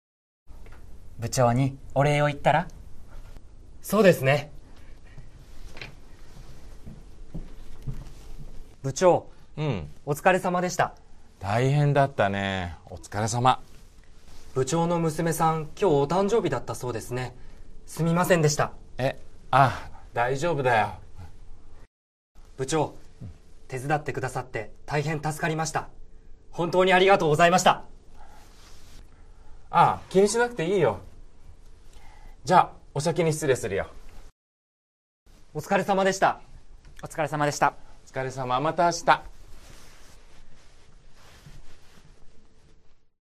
Role-play Setup
skit01.mp3